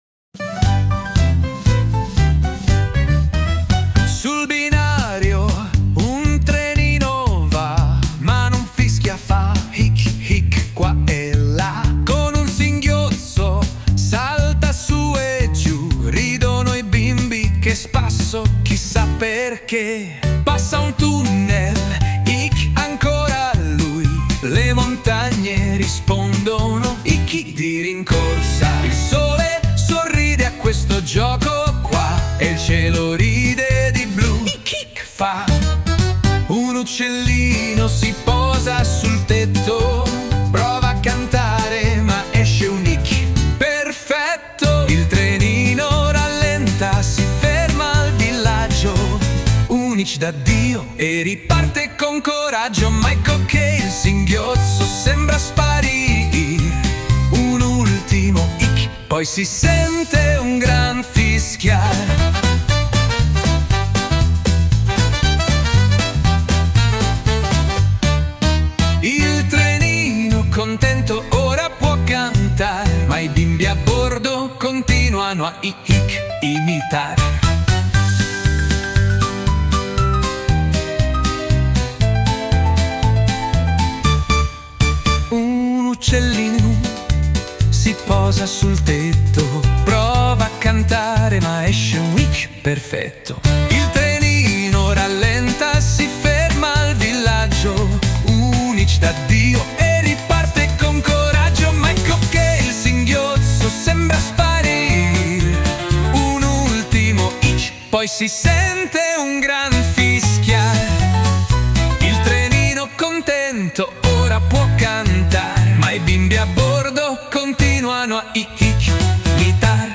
🎶 Filastrocche